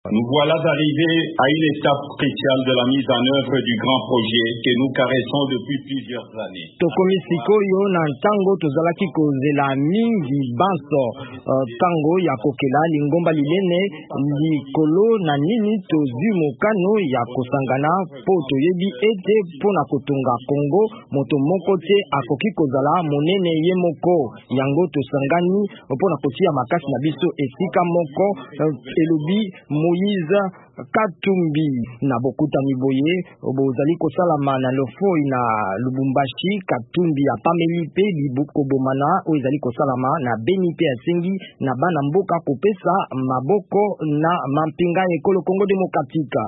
Moïse Katumbi mokambi ya Ensemble alobi ete lingomba lizali kokanisa ndenge nini kobongwana na parti politiki. Alobi yango na bokutani ya Lofoi, na Lubumbashi, Haut-Katanga.